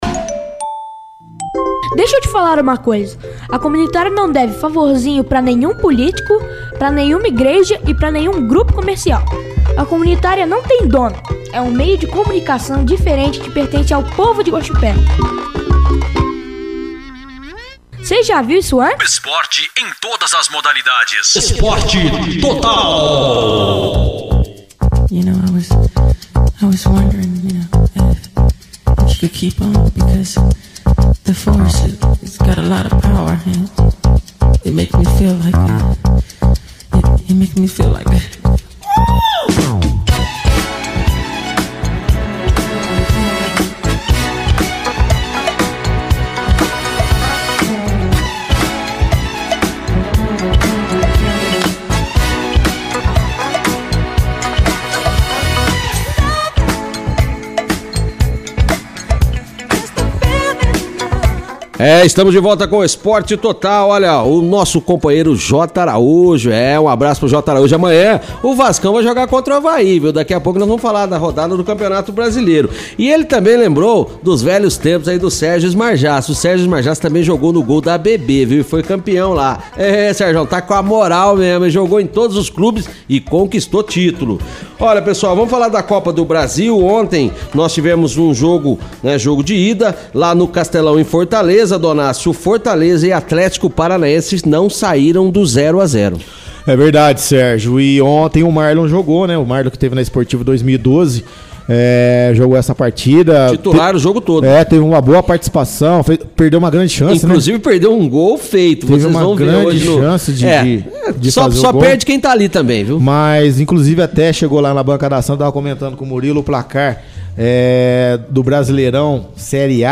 O “Esporte Total” desta sexta (17/05) contou com a participação do Diretor Municipal de Esportes, Murilo Galatte, que nos esclareceu algumas dúvidas referente a disponibilidades de quadras na cidade para a realização desta competição além de dar detalhes sobre algumas novidades deste ano.